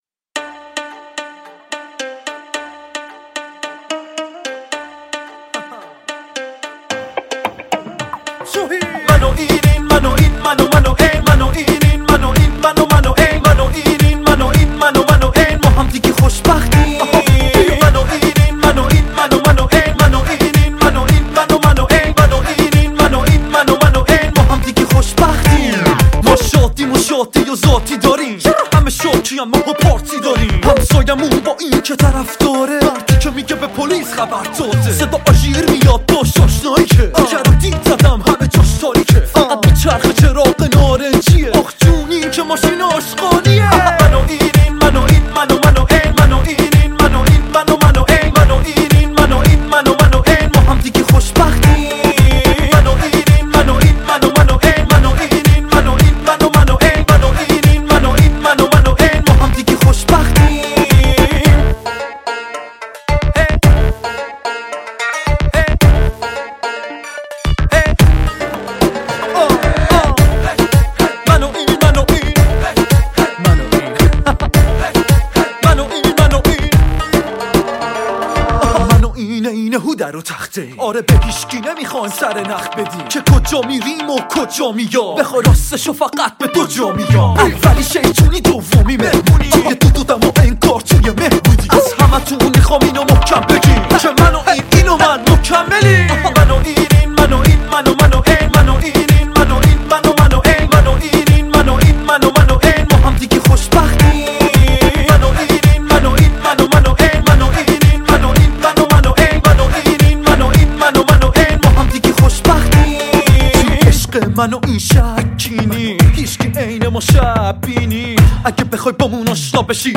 ژانر: پاپ & هیپ هاپ